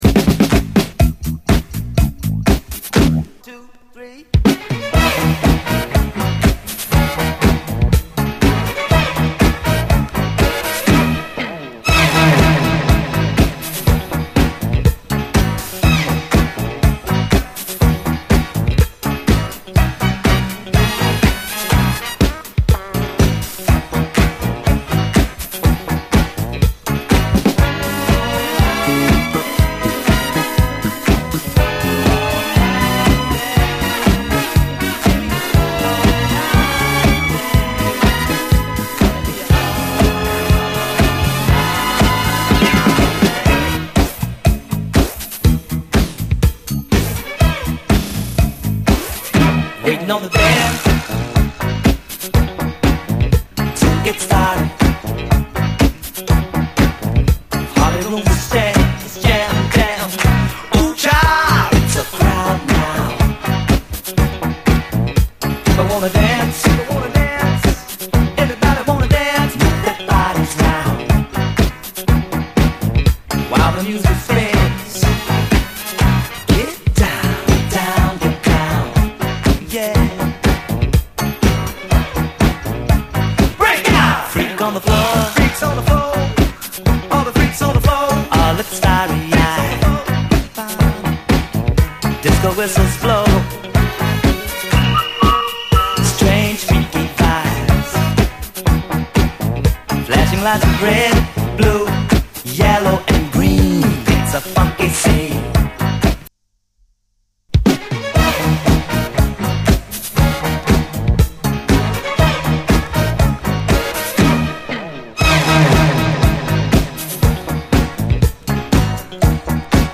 SOUL, 70's～ SOUL, DISCO
ガラージ・クラシックの重量級ファンキー・ブギー！ダビーなエフェクトのインスト・ヴァージョンもDJユース！
ファットでベタベタなディスコ・クラシック感がむしろ抜けがよくてカッコいいです！